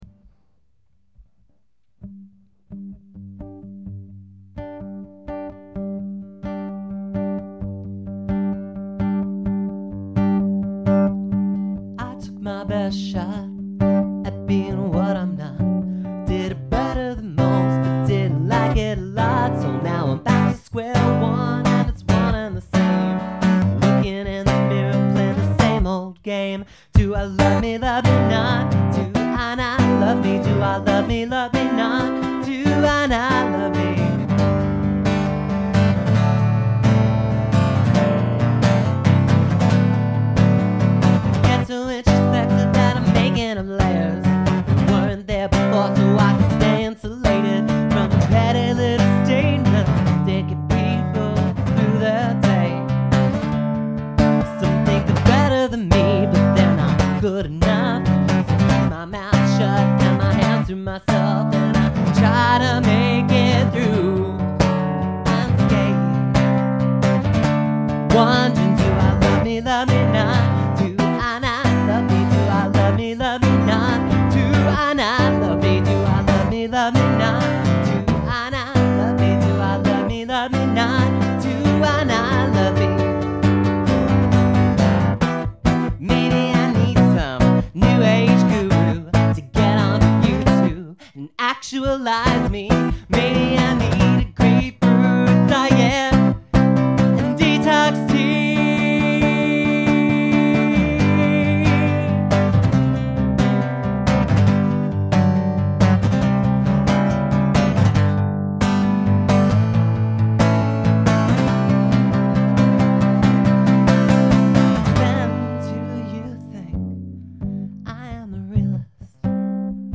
After a few weeks the chorus made its way up the neck from open chords to the 14th fret.
And now I am debuting it to the world at large with its first proper demo recording.